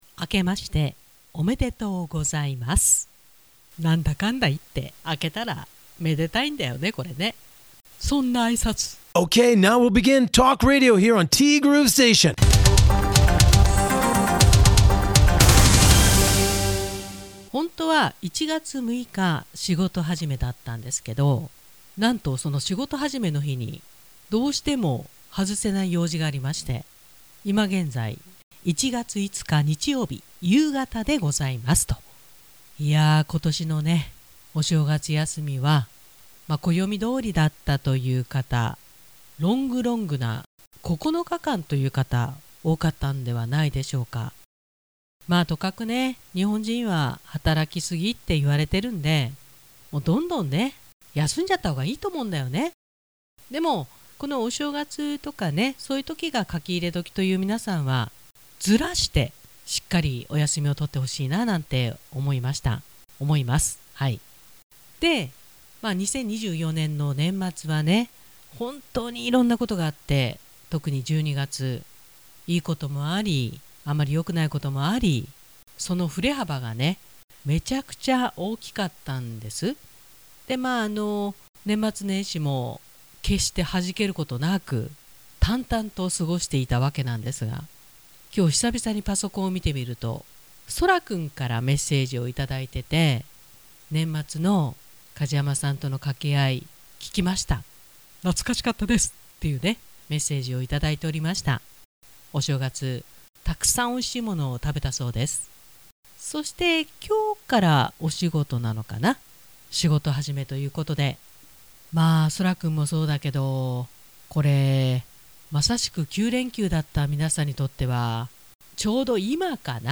ぶっちゃけ言ってしまうと 仕事始めである本日の放送は 本日月曜日に とても大切な用事があるため 昨日の夜に収録・編集いたしました。